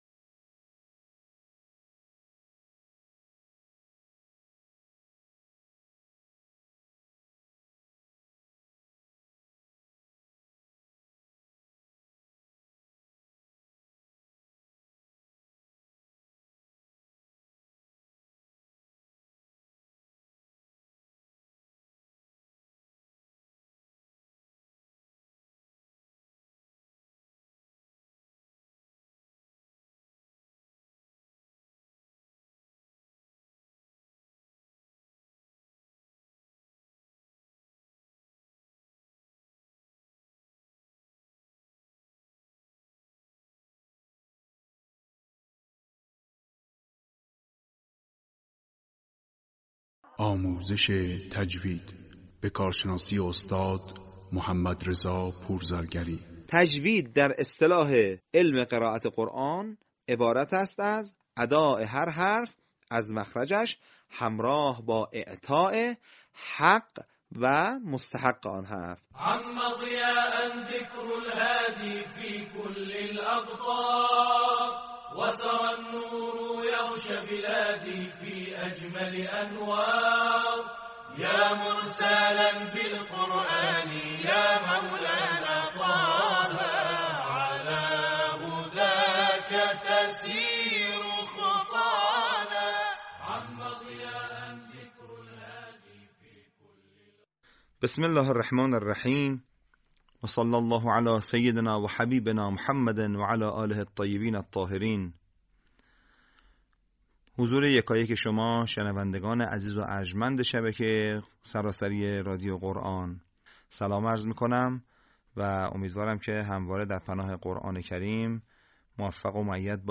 به همین منظور مجموعه آموزشی شنیداری(صوتی) قرآنی را گردآوری و برای علاقه‌مندان بازنشر می‌کند.